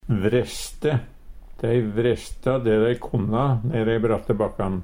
vreste - Numedalsmål (en-US)